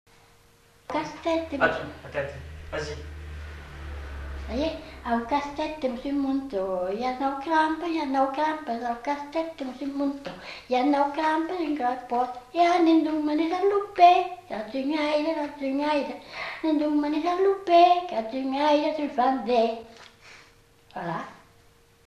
Aire culturelle : Gabardan
Genre : chant
Effectif : 1
Type de voix : voix de femme
Production du son : chanté
Danse : congo